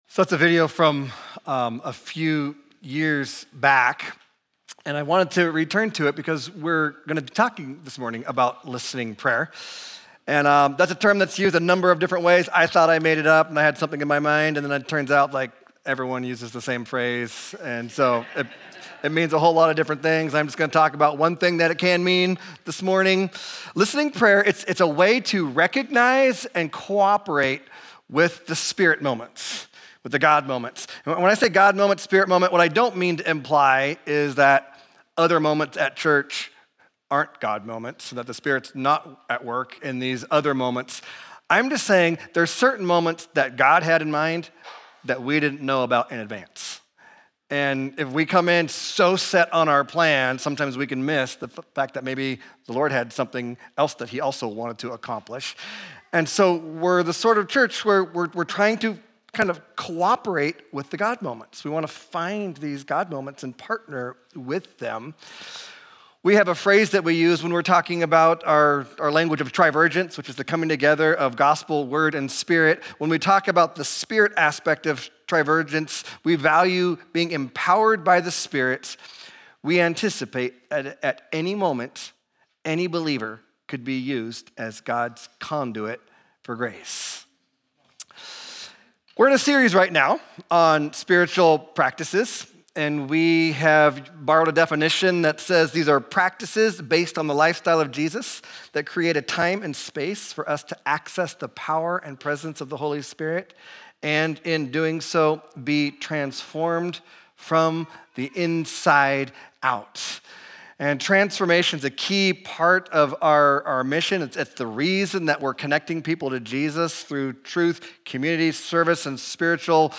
**Video referenced in the beginning of the sermon: